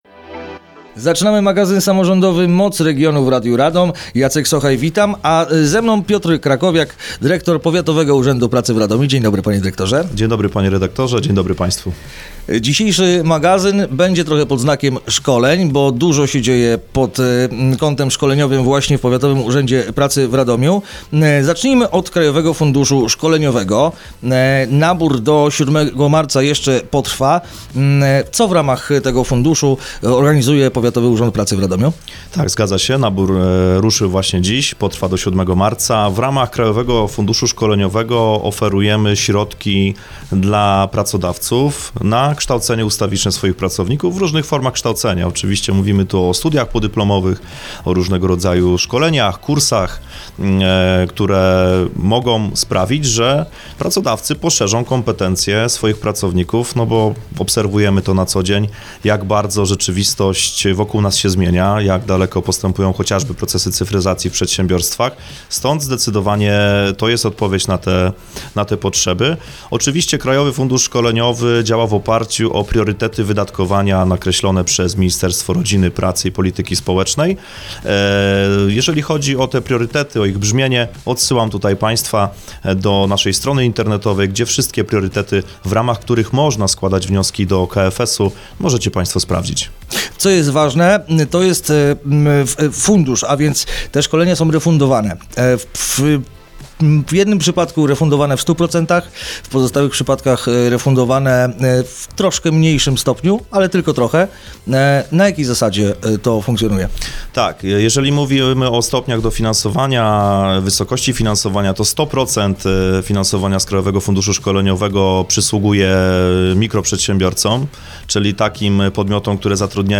Rozmowa dostępna jest również na facebookowym profilu Radia Radom: